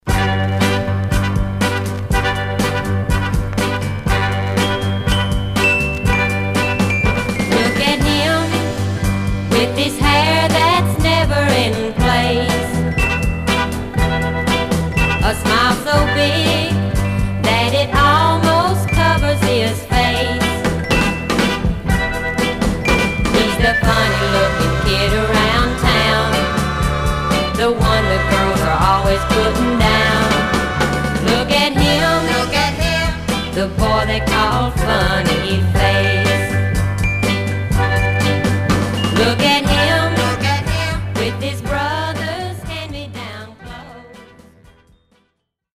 Stereo/mono Mono
White Teen Girl Groups